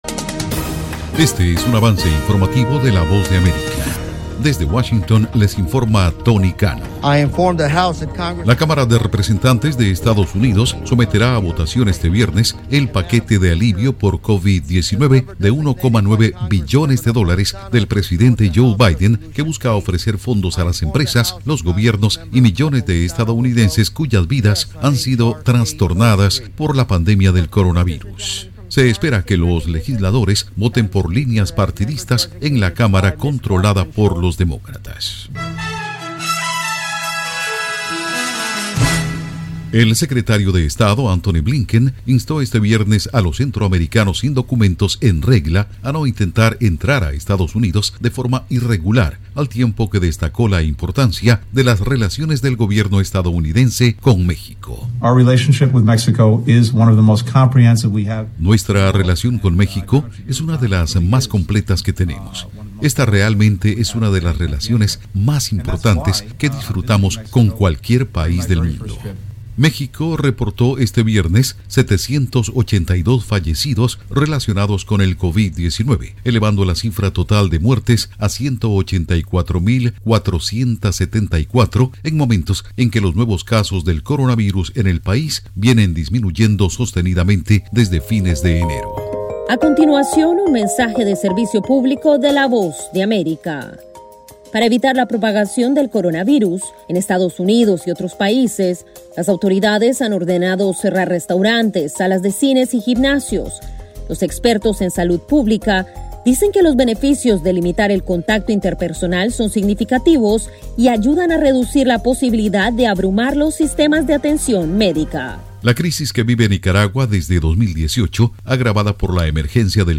Cápsula informativa de tres minutos con el acontecer noticioso de Estados Unidos y el mundo